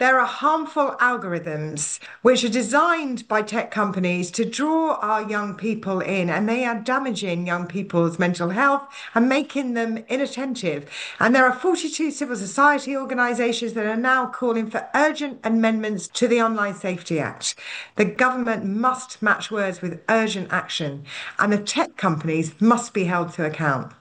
Speaking to Radio Jackie she said over 2,000 parents in her constituency have contacted her wanting immediate changes.